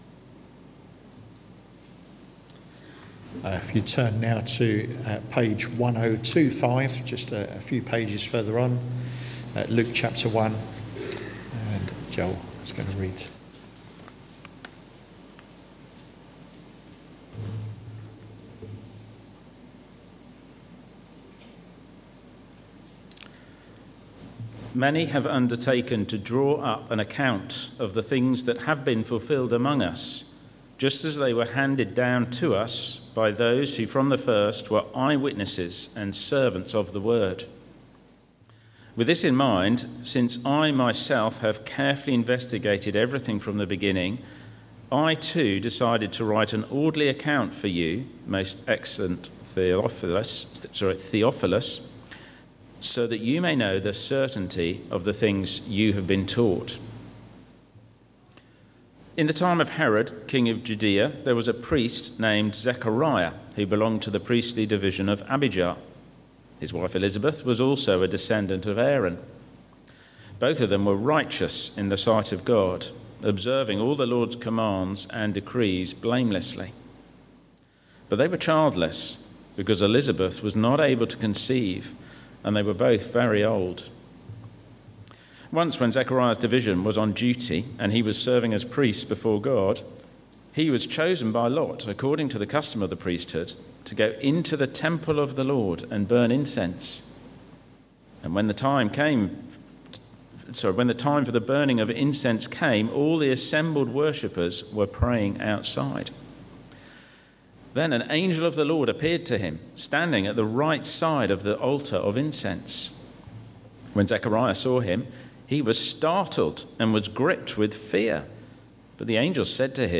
Sermons – Dagenham Parish Church
Service Type: Sunday Morning